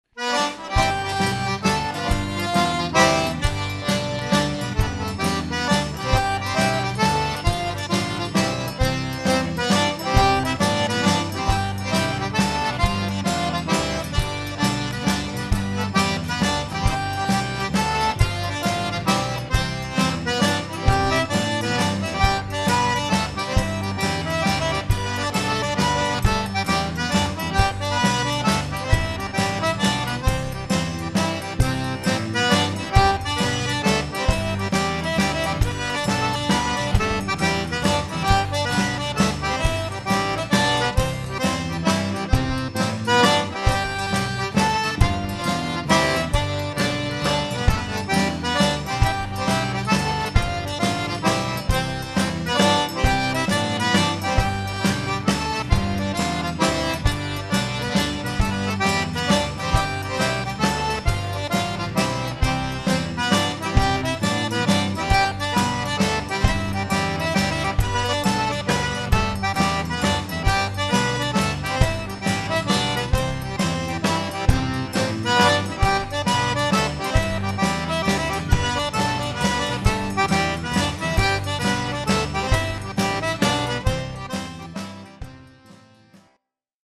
2005 is going to be another great year to travel to SW Louisiana and learn more about playing the Cajun accordion, dancing and eating.
These clips were paired down to a 9600 bit rate as MP3's so they stayed small.